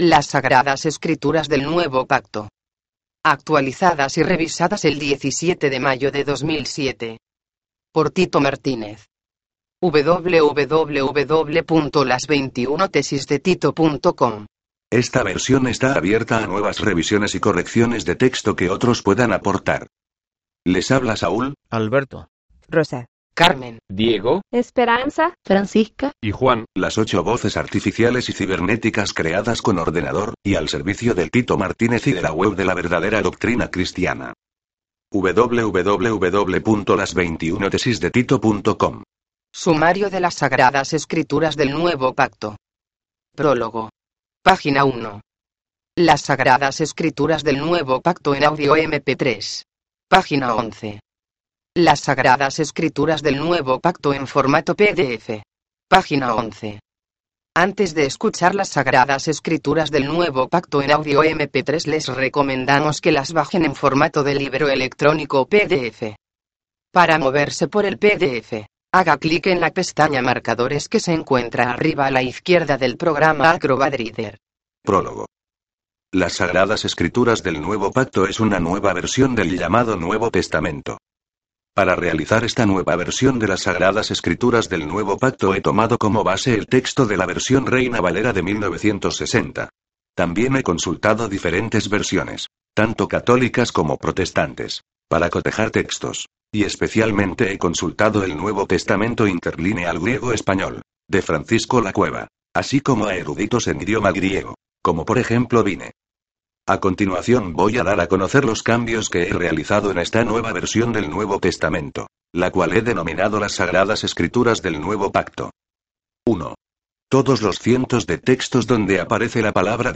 Tengo el gusto de ofrecerles las Sagradas Escrituras del Nuevo Pacto le�das por ocho voces artificiales y cibern�ticas creadas con ordenador.